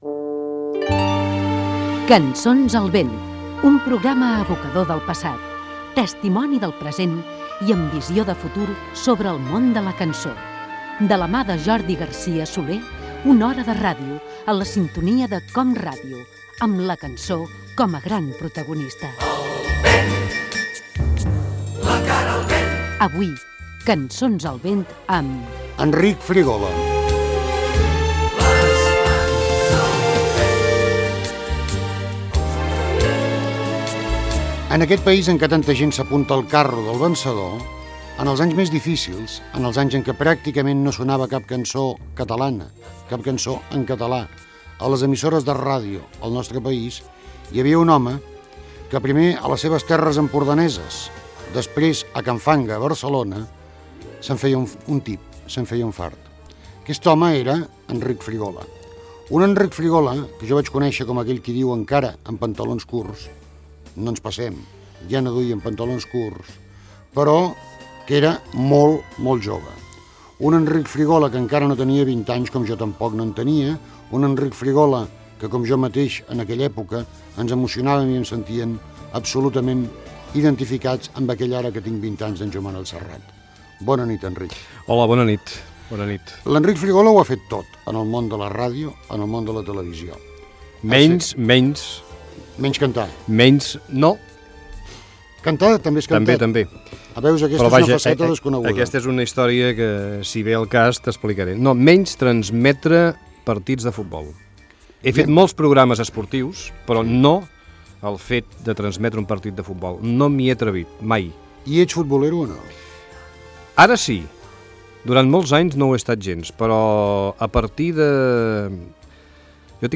Entreteniment
Fragment extret de l'arxiu sonor de COM Ràdio.